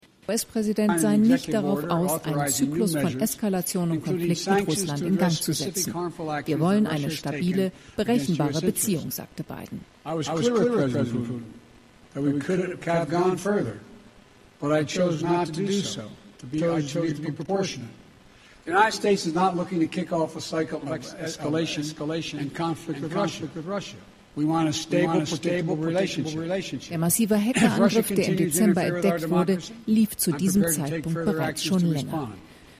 Legt man dann die Tonspur des Kölner Senders auf die originale Tonaufzeichnung von CNBC, dann ergibt sich im A/B-Kanalabgleich ab dem benannten Versprecher ein folgerichtiger Tonversatz von 544 Millisekunden – also halber Sekunde.
AB-Kanal-links-CNBC-rechts-DLF-33sec.mp3